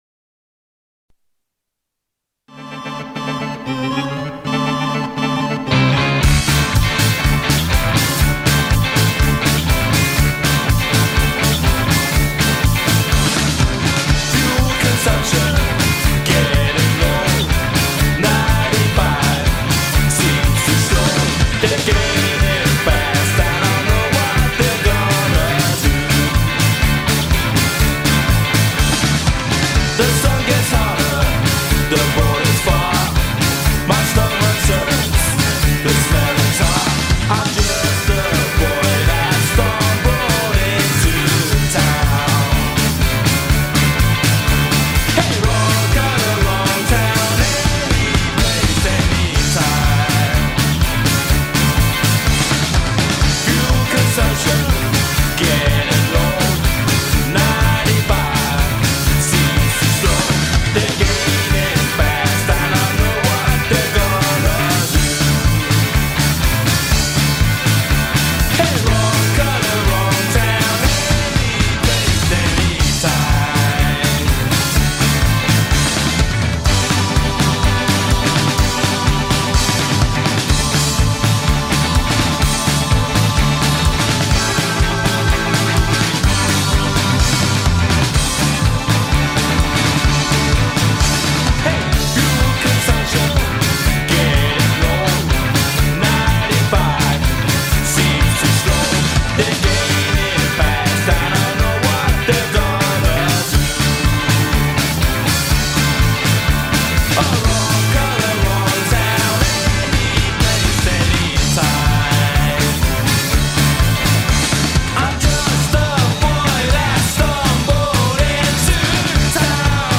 in session tonight
vocals, guitar
bass guitar
lead guitar, percussion, backing vocals
drums
occasional trumpet and keyboards